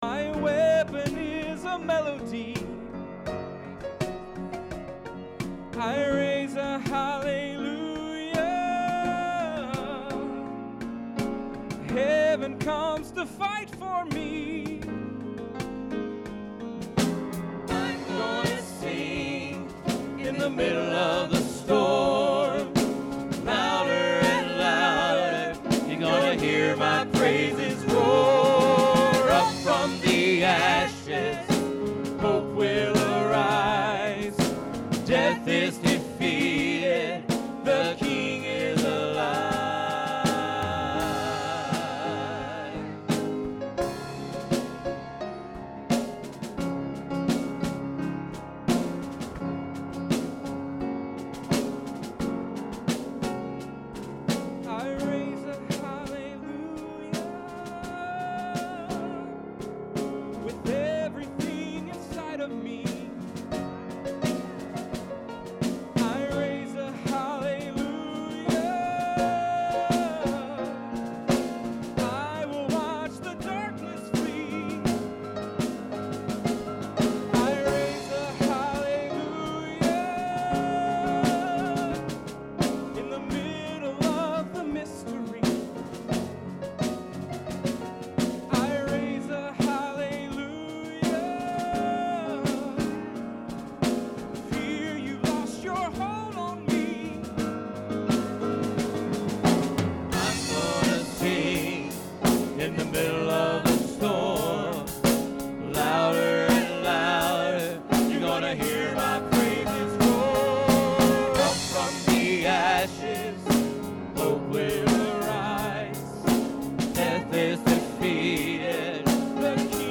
Sermons - Biltmore Church of God